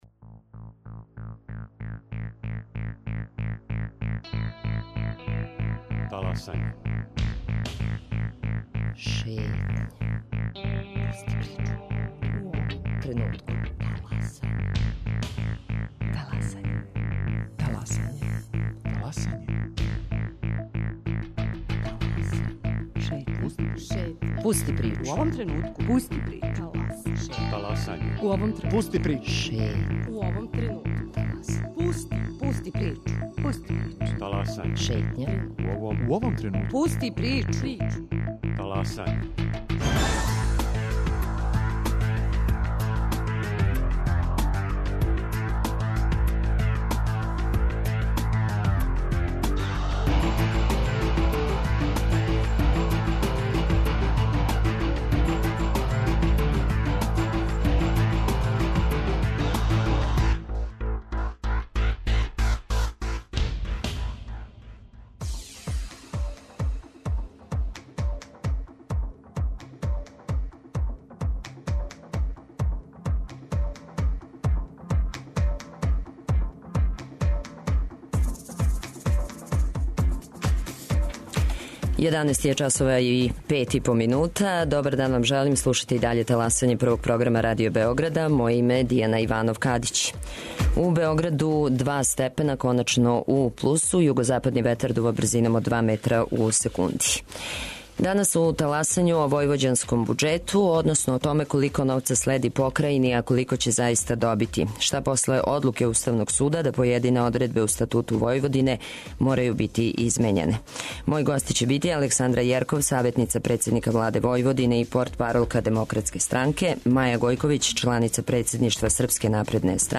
Гости: Александра Јерков, саветница председника Владе Војводине и портпаролка Демократске странке; Маја Гојковић, чланица председништва Српске напредне странке и Бојан Костреш, заменик председника Лиге социјалдемократа Војводине.